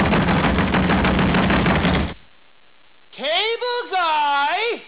NOTE, most of them are dialogs from movies.